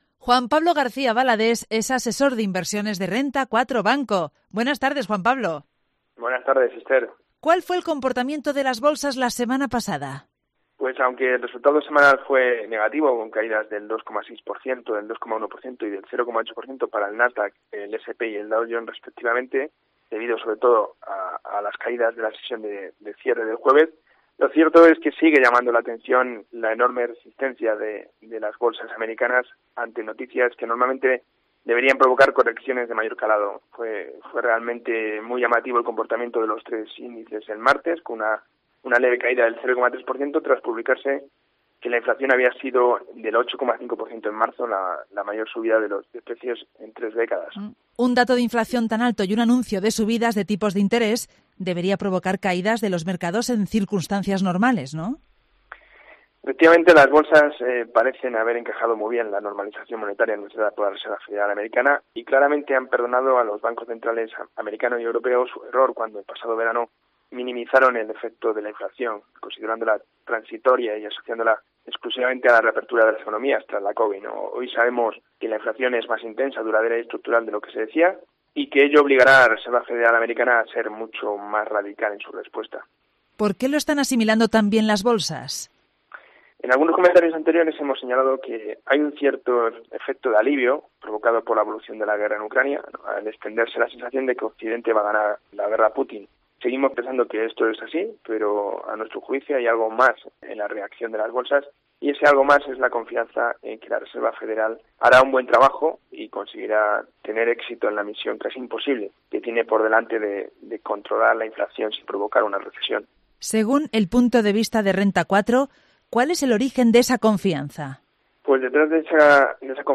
interviene un martes más en el programa local de Cope León para explicar el comportamiento de las Bolsas la semana pasada.